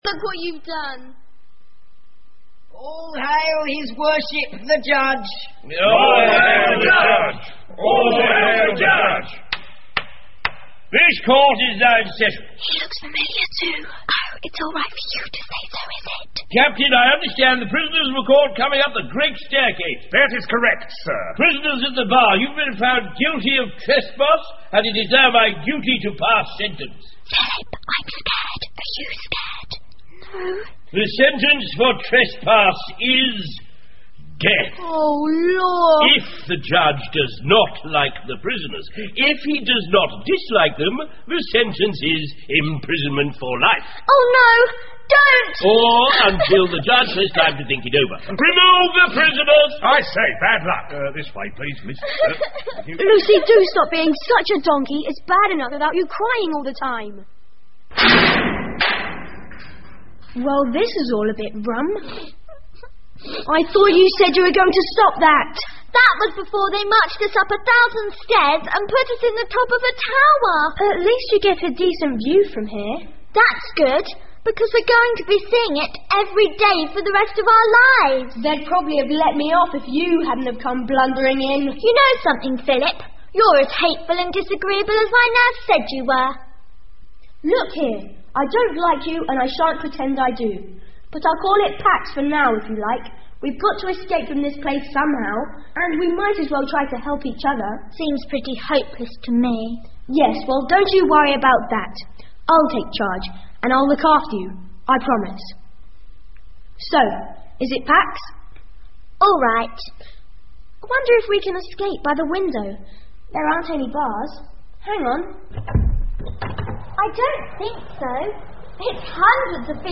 魔法之城 The Magic City by E Nesbit 儿童广播剧 7 听力文件下载—在线英语听力室